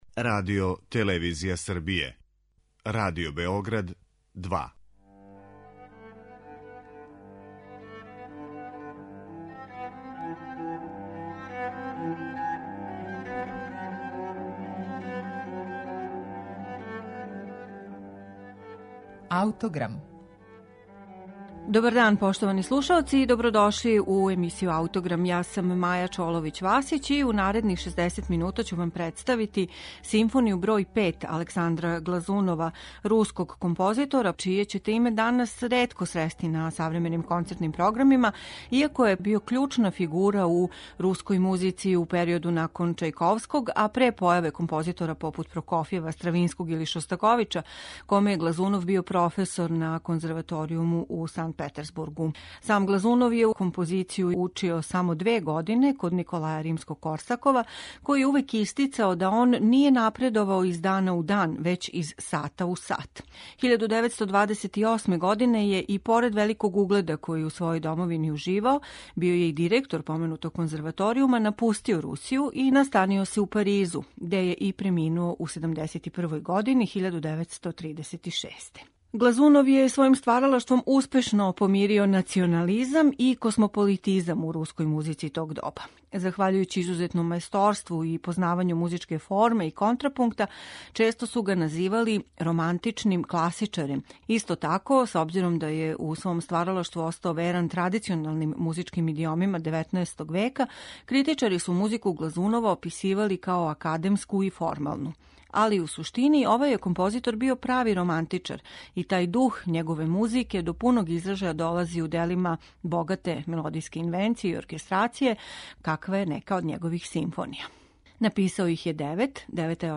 Сифмонију у вечерашњој емисији изводи Симфонијски оркестар СССР- а, под диригентском управом Јевгенија Светланова.